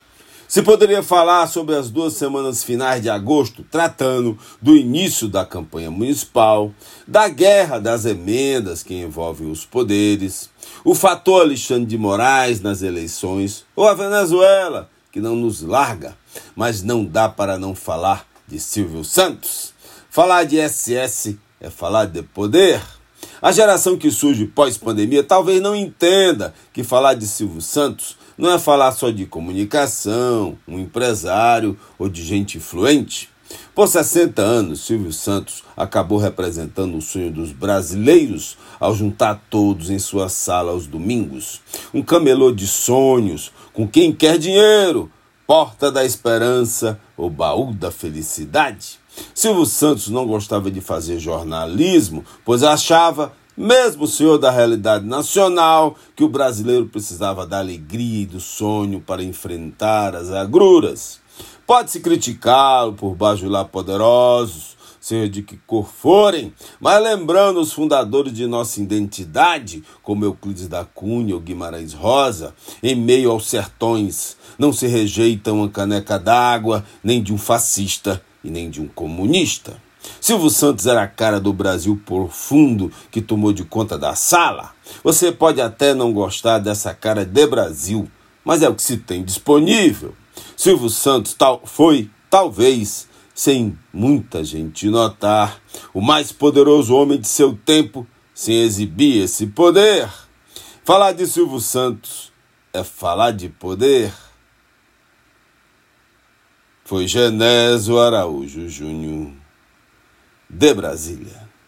Comentário desta segunda-feira
direto de Brasília.